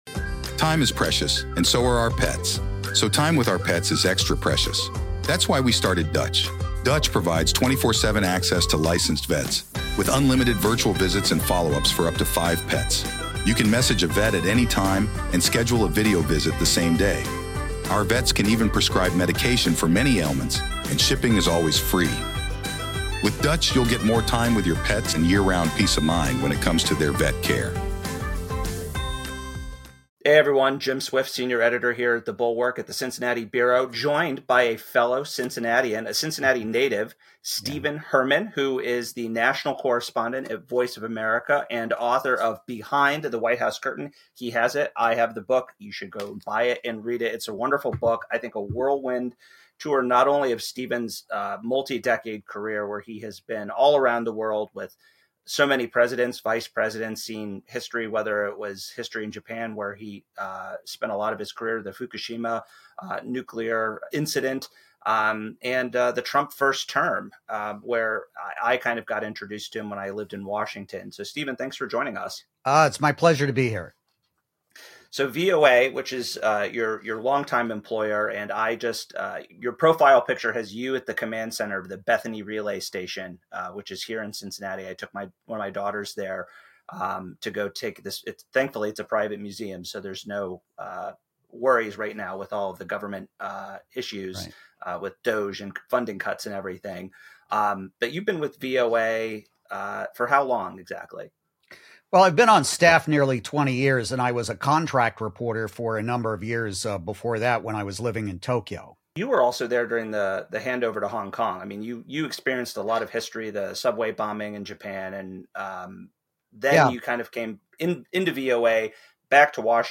The interview reviews VOA’s historical impact from WWII to its modern role, warning that the current hiatus may let rival state-sponsored broadcasters fill the void.